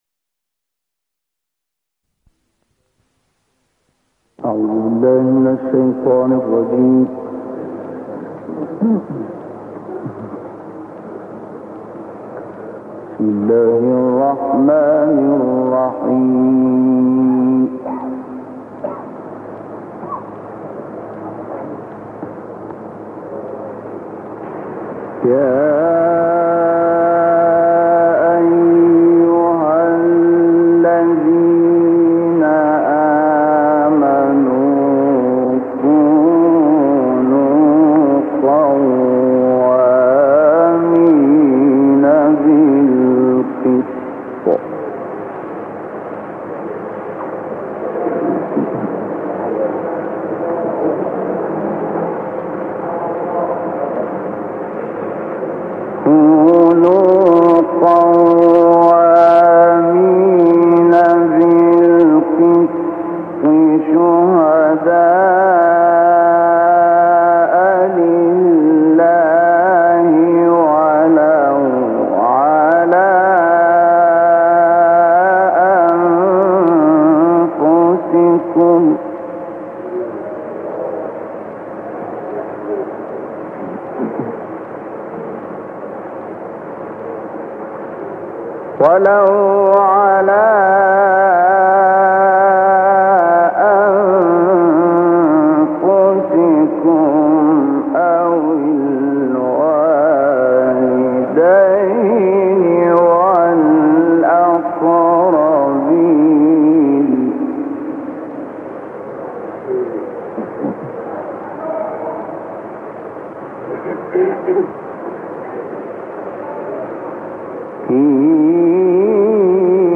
کانون خبرنگاران نبأ: سرعت تلاوت استاد شحات محمد انور با آن ویژگی‌هایی که در صدای ایشان وجود دارد یک نوع تناسب و همگونی ایجاد کرده است که در واقع منجر به یک طمأنینه و وقاری در سبک ایشان شده که در قراء ماقبل ایشان کمتر سابقه داشت.